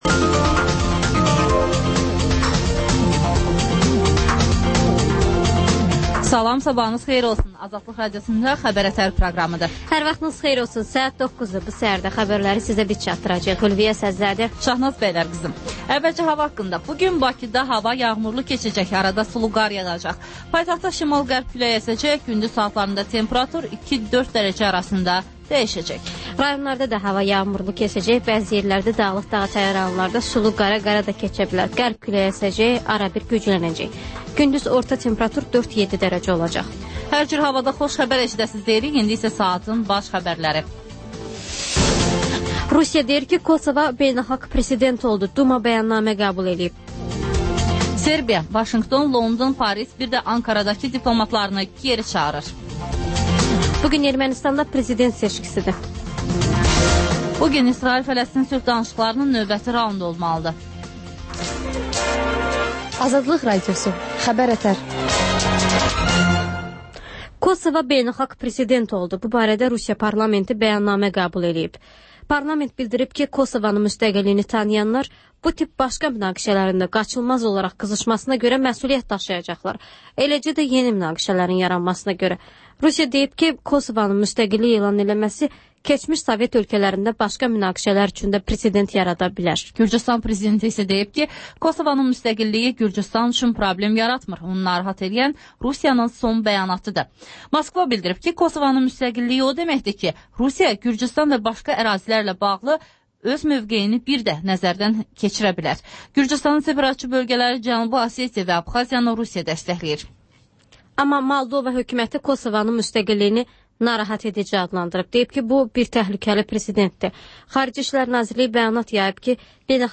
Xəbər-ətər: xəbərlər, müsahibələr, sonra TANINMIŞLAR verilişi: Ölkənin tanınmış simalarıyla söhbət